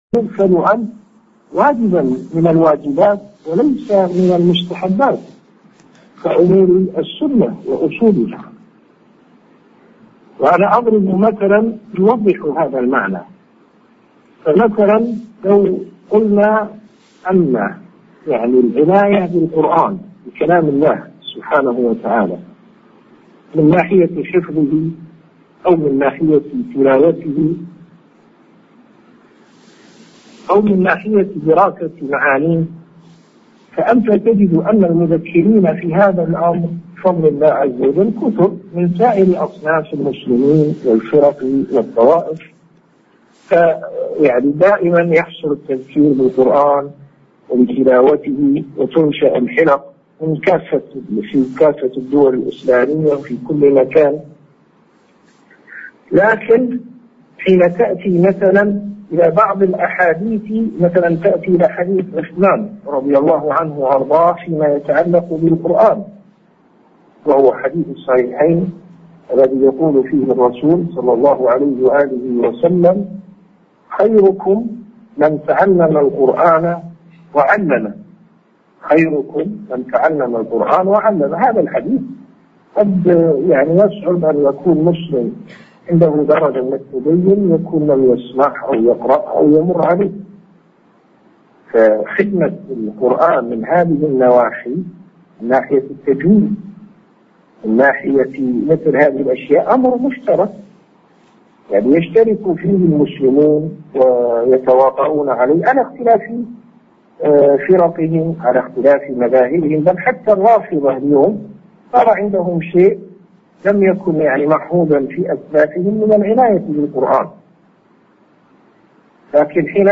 مكالمة هاتفية مع بعض الإخوة في سريلانكا